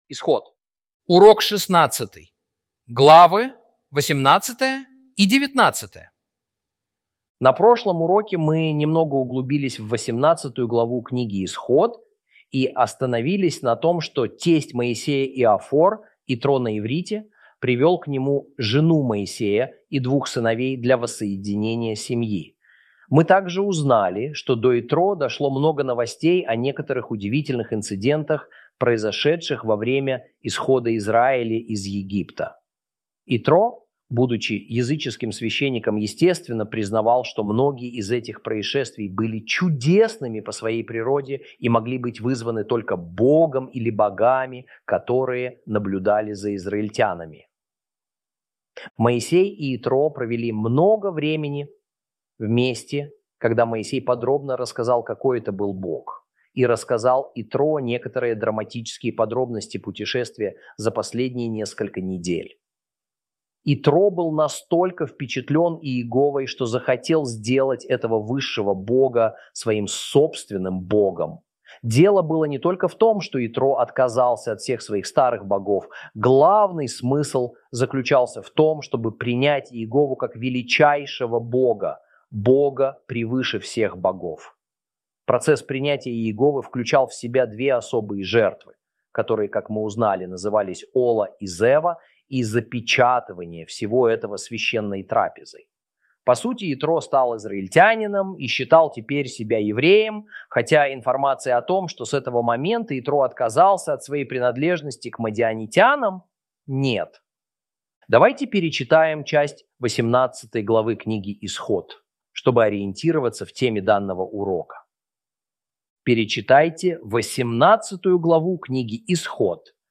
Урок 16 - Исхо́д 18 & 19 - Torah Class